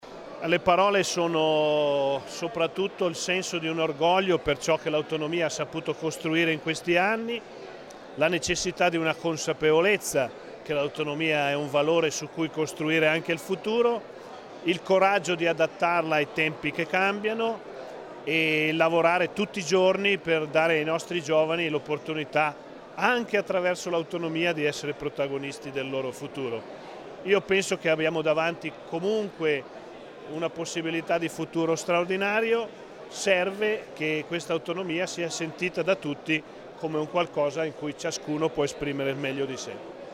intervista_Rossi_5_settembre_MP3_192K.mp3